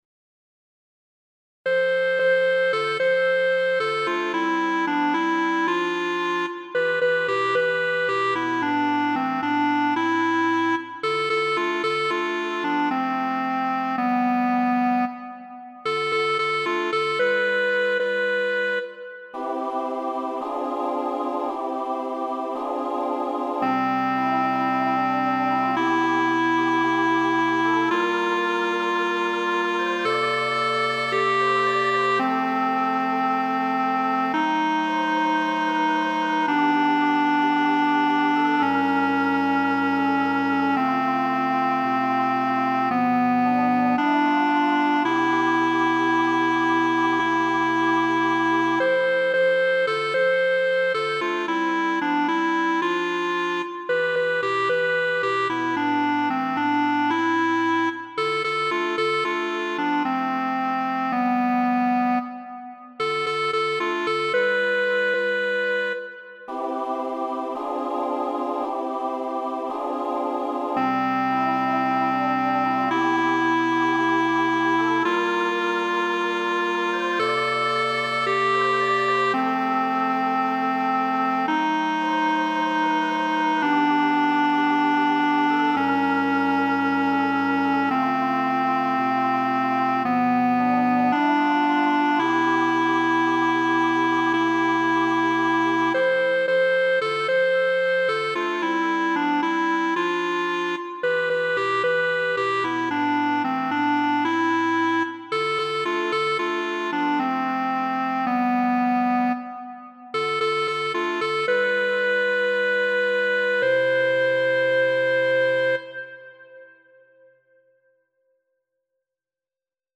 Intonation :
soprano - alto - baryton - TUTTI